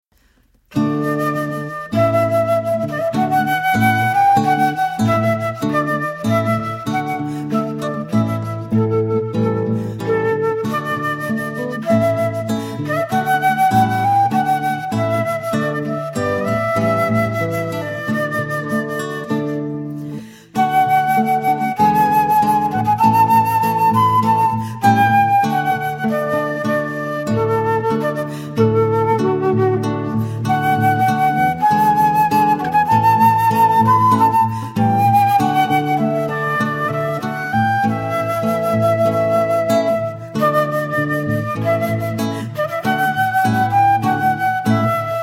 From classical flute to sophisticated jazz
Wedding Music Samples